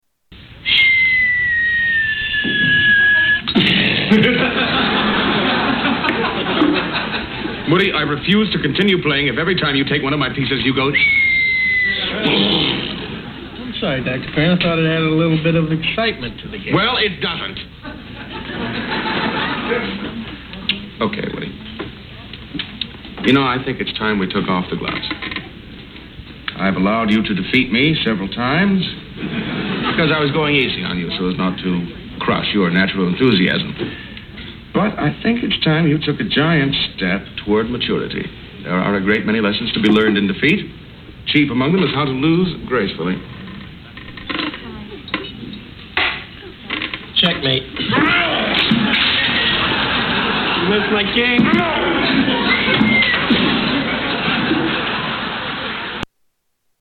Bombing noises